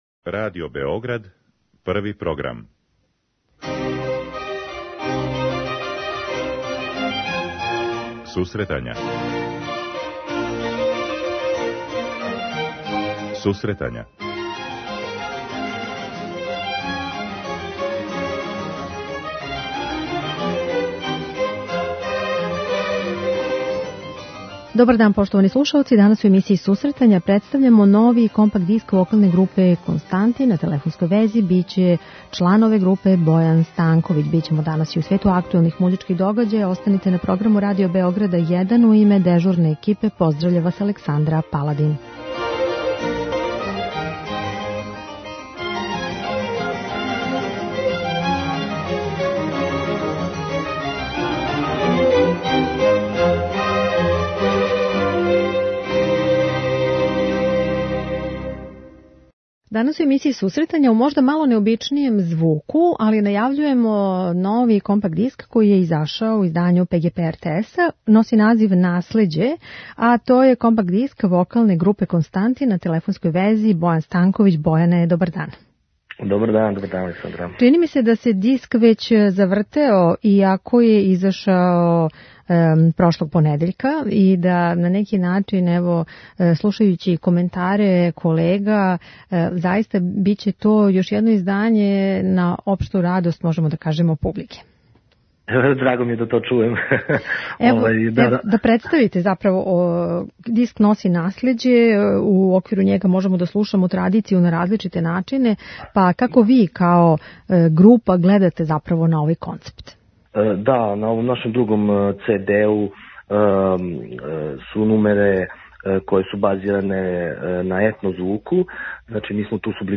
Такође, представићемо и актуелне догађаје који ће обележити наредну музичку недељу. преузми : 10.25 MB Сусретања Autor: Музичка редакција Емисија за оне који воле уметничку музику.